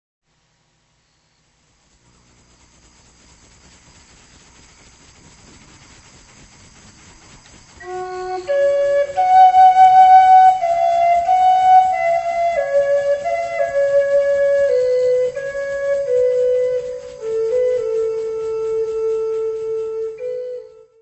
: stereo; 12 cm + folheto